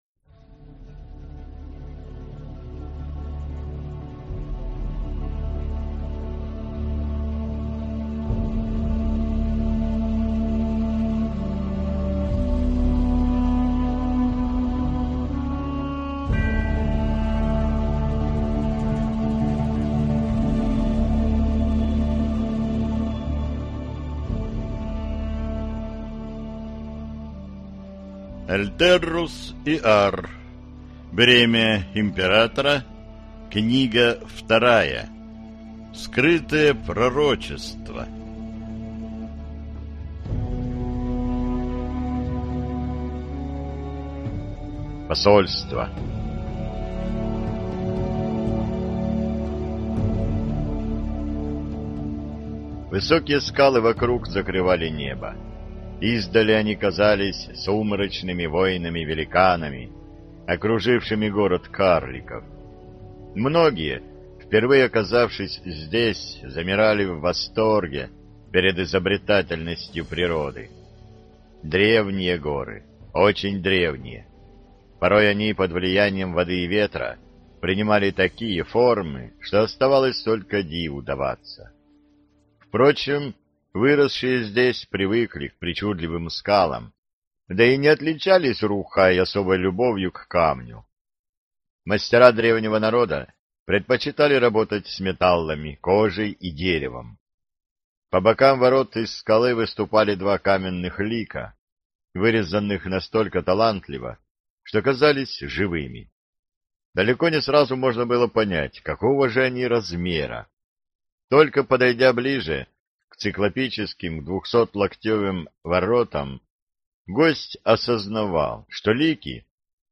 Аудиокнига Бремя императора: Скрытое пророчество | Библиотека аудиокниг